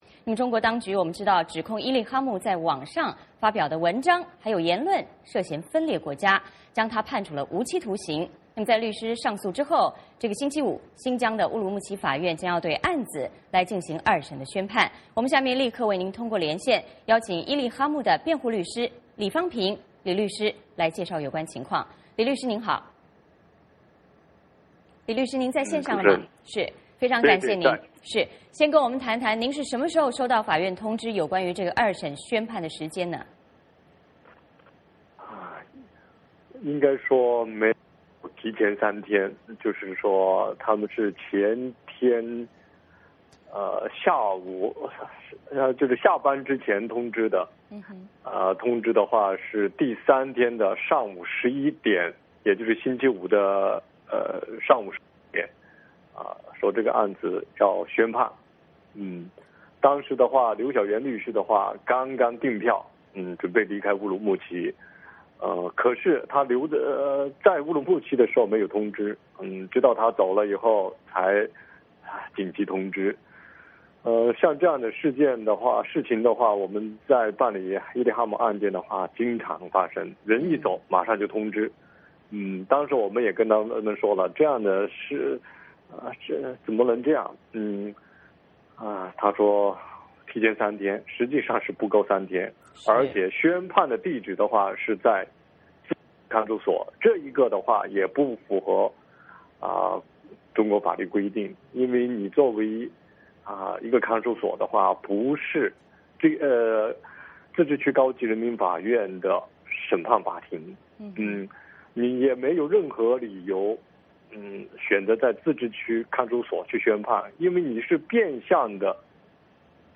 VOA连线：伊力哈木案将终审，律师批评程序不公